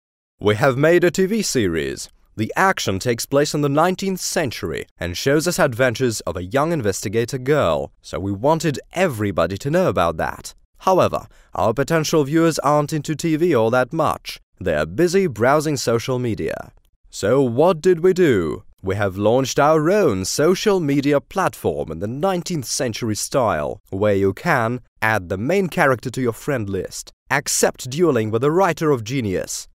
tvseДиктор-мужчина на английском (043)